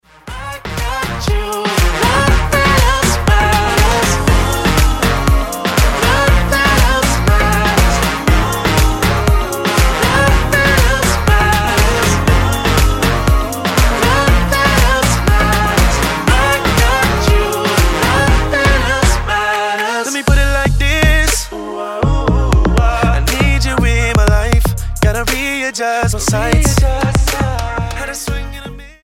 Dance/Electronic Single